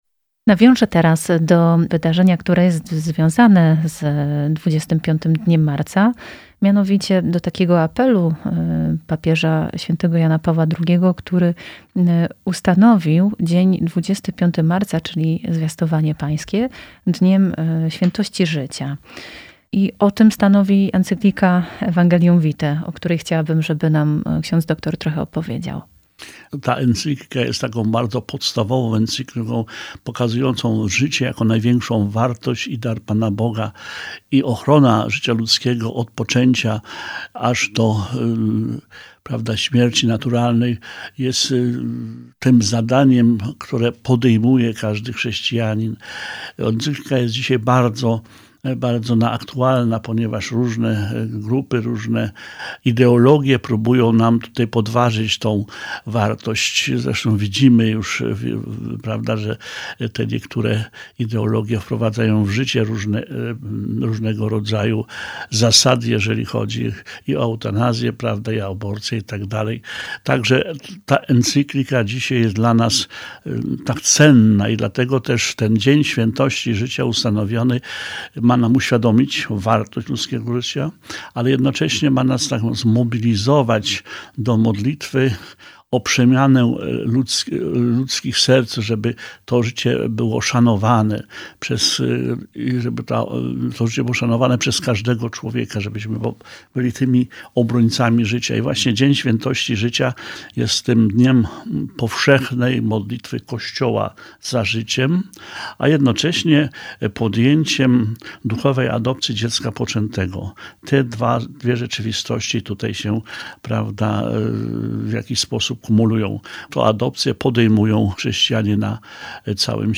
W jednej z ostatnich audycji rozmawialiśmy o towarzyszeniu rodzinom, o świętości i ochronie życia od poczęcia do momentu naturalnej śmierci [marzec 2021].